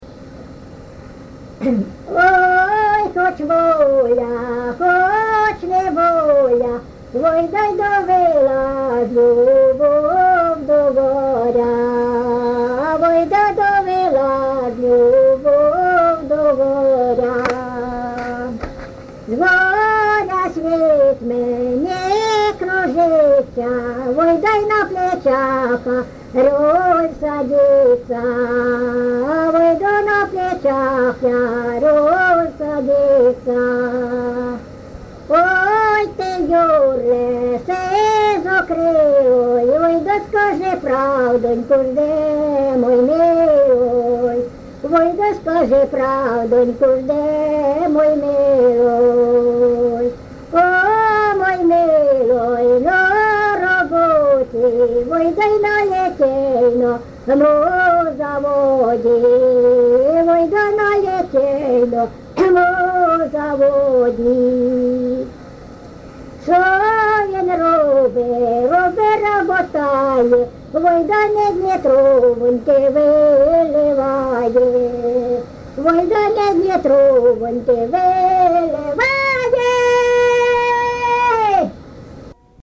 ЖанрПісні з особистого та родинного життя
Місце записус. Ярмолинці, Роменський район, Сумська обл., Україна, Слобожанщина